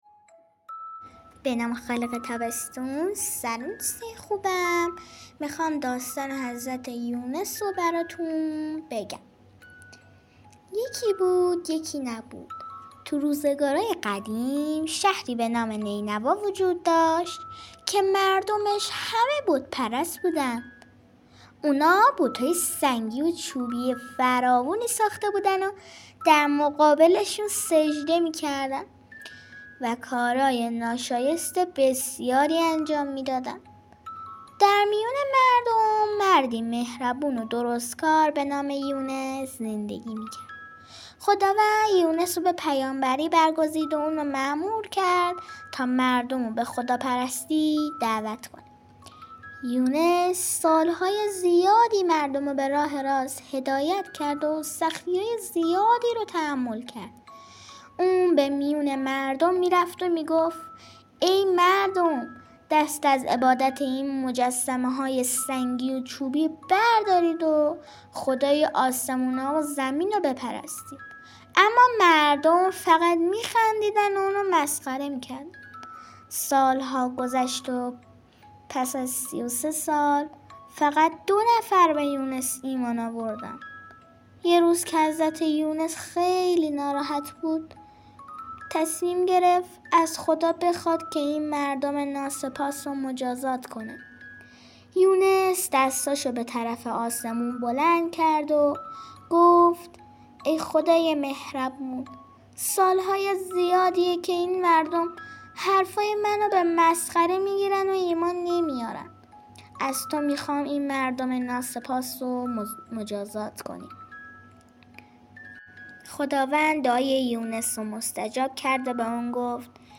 قصه صوتی | حضرت یونس